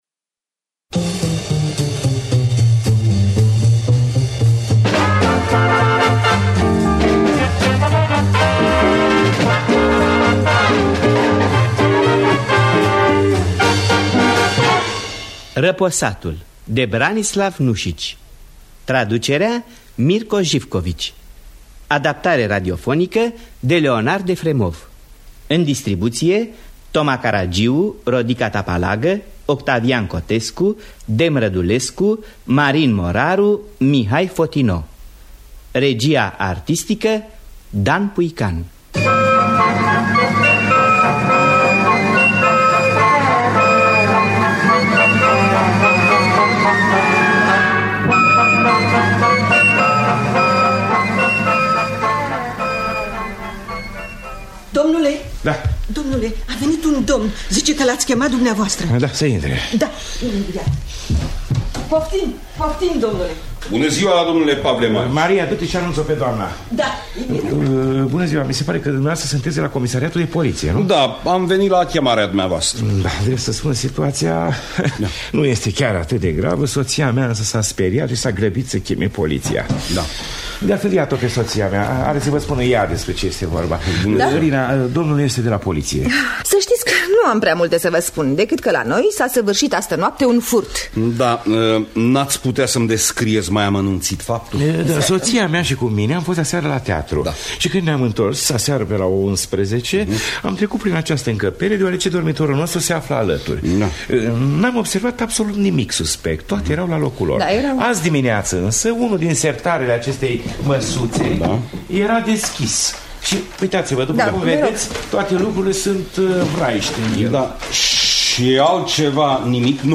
Răposatul de Branislav Nuşici – Teatru Radiofonic Online